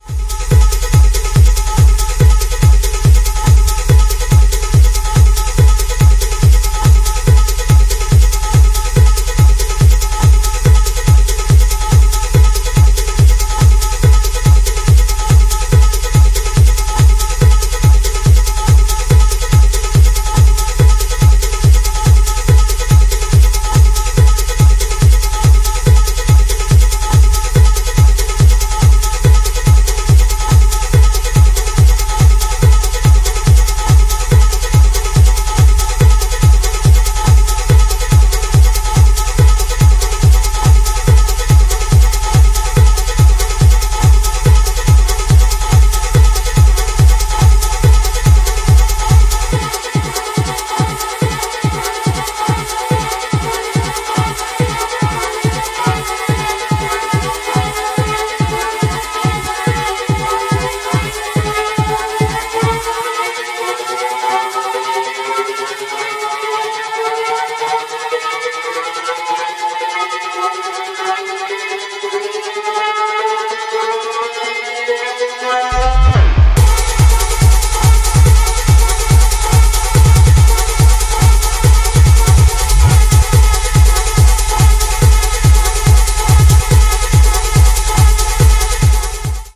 four hard-hitting techno cuts